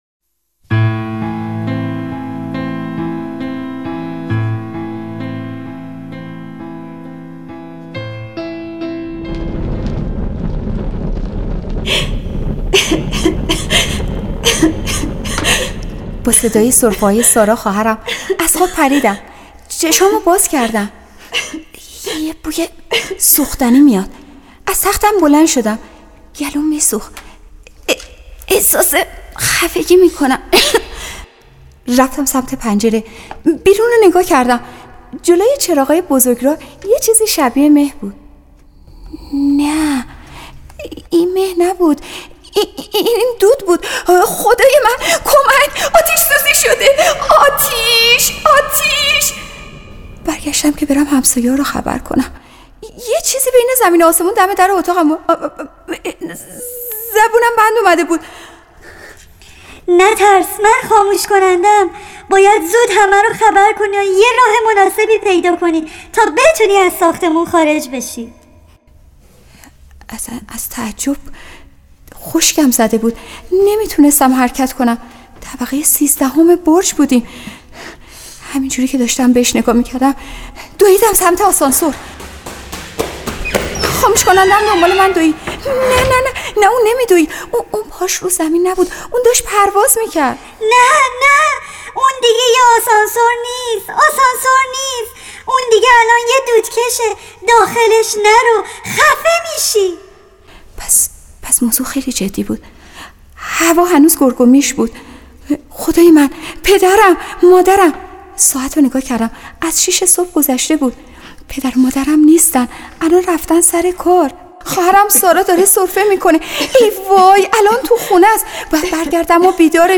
آموزش ایمنی با قصه خوانی مربیان آتش نشان + داستان سارا